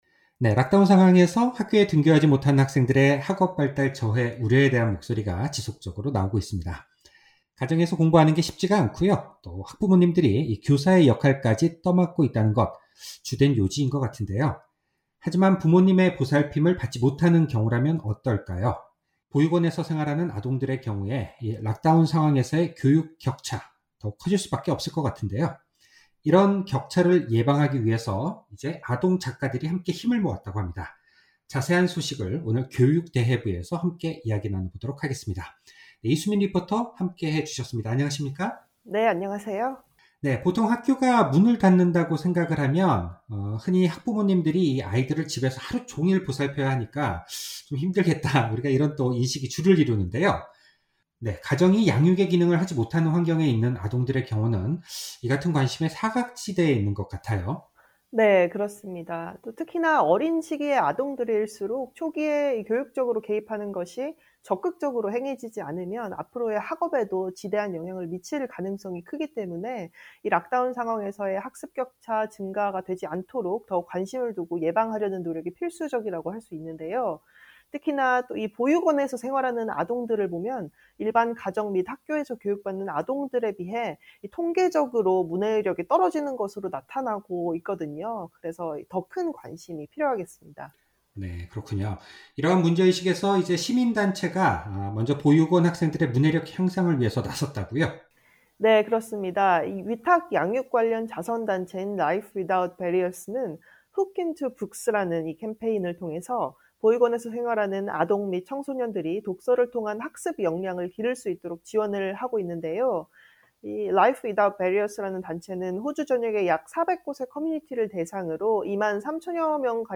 리포터